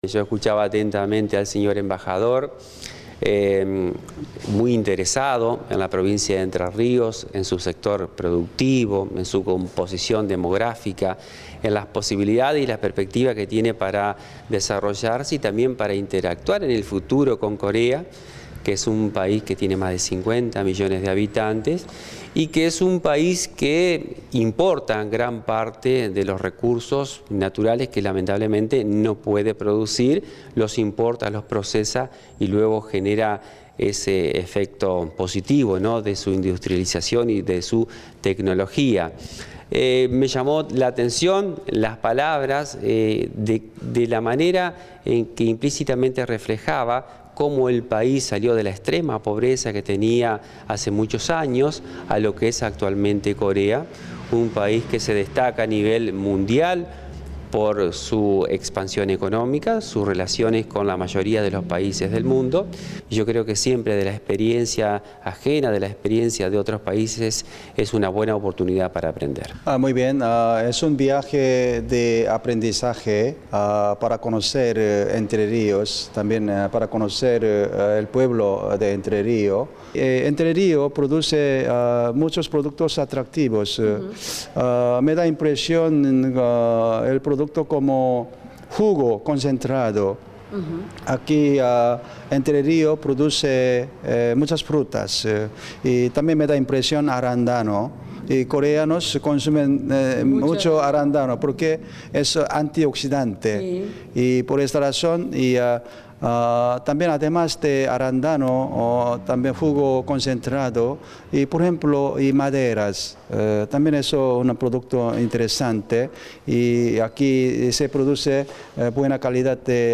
El vicegobernador de la provincia, Adán Bahl habló esta mañana tras el encuentro que mantuvo con el embajador de Corea, Jong Youn Choo.